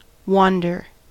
wander-us.mp3